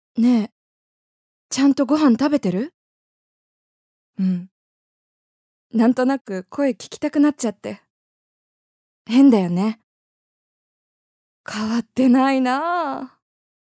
ボイスサンプルはこちら↓
セリフ@